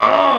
File:Kremling SFX 1.oga
Kremling's defeat sound with real pinch.